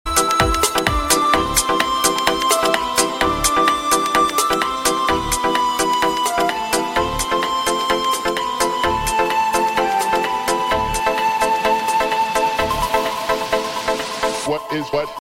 Hip-Hop Ringtones